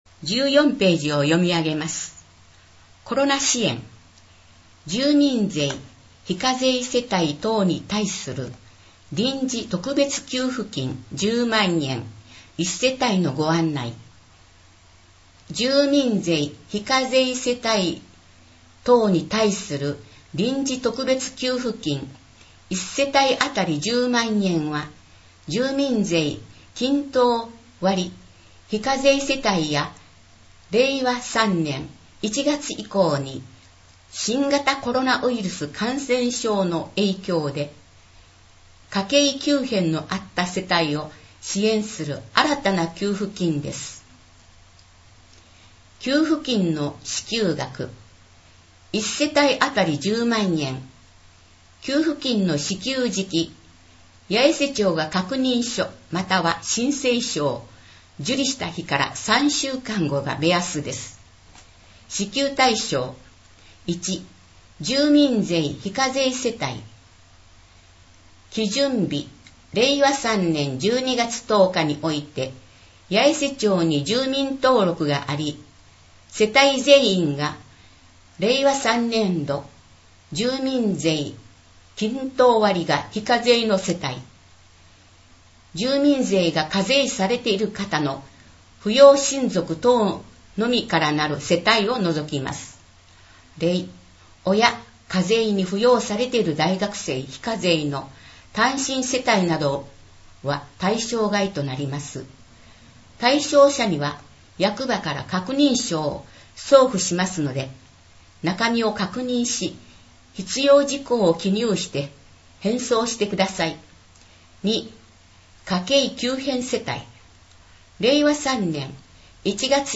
この音声は「音訳サークルやえせ」の皆さんのご協力で作成しています。